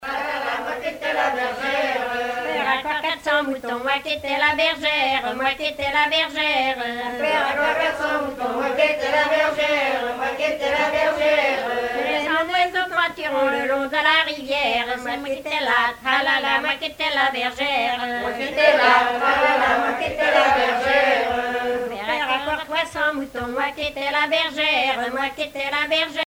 danse : laridé, ridée
Genre énumérative